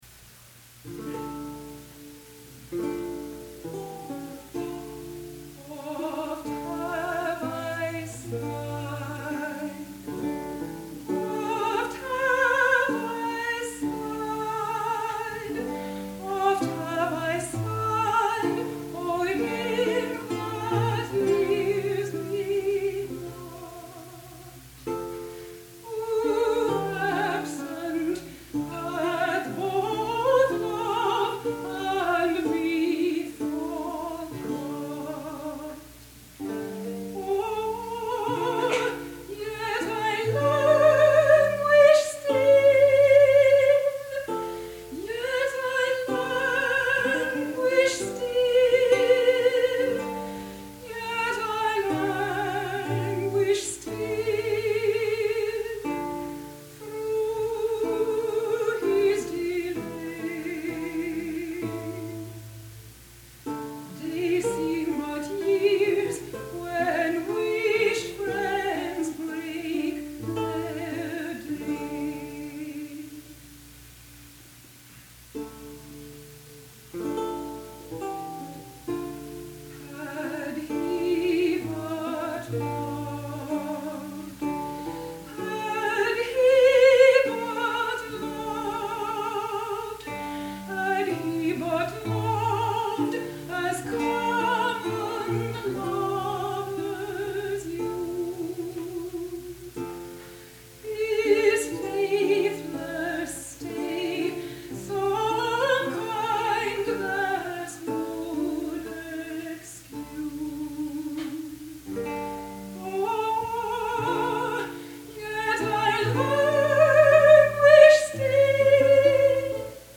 This dolorous love lament is from Campion’s ‘Third Book of Lute-songs’.
soprano
lute